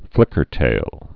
(flĭkər-tāl)